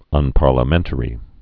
(ŭnpär-lə-mĕntə-rē, -mĕntrē)